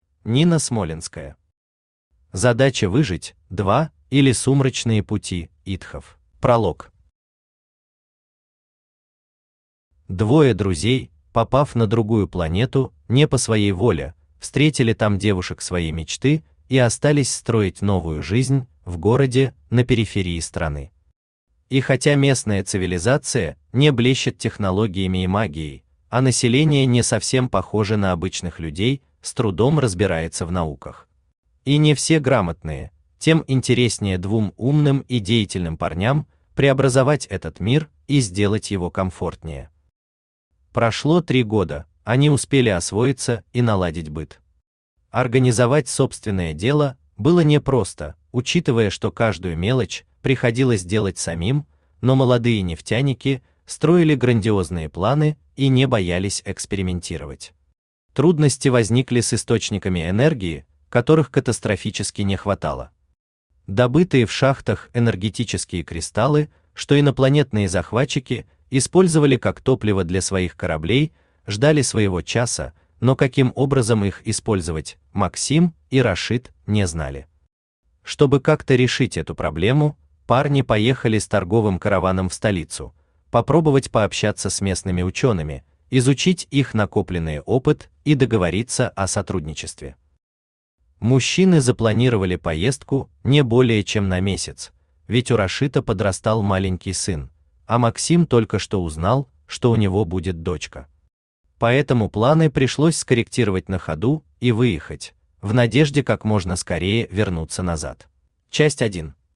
Аудиокнига Задача выжить – 2, или Сумрачные пути идхов | Библиотека аудиокниг
Aудиокнига Задача выжить – 2, или Сумрачные пути идхов Автор Нина Смолянская Читает аудиокнигу Авточтец ЛитРес.